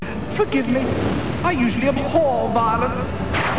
Spot Sound Bytes!
From Spider-Man: the Animated Series.